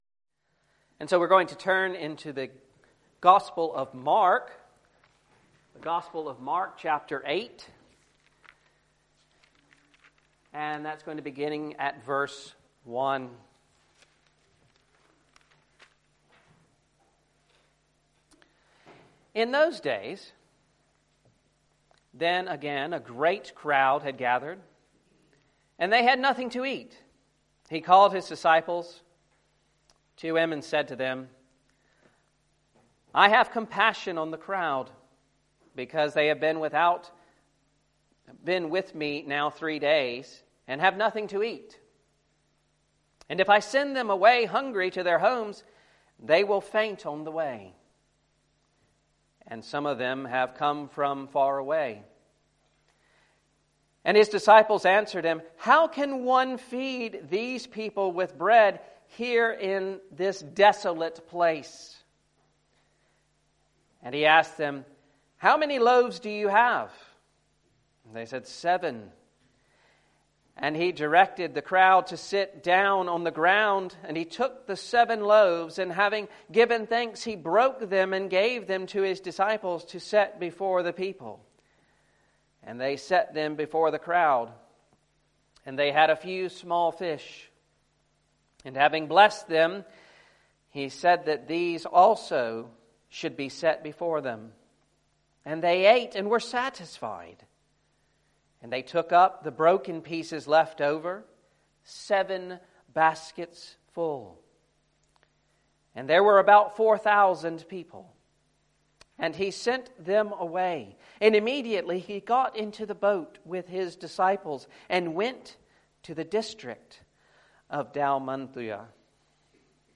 Mark 8:1-21 Service Type: Sunday Evening Reading and Sermon Audio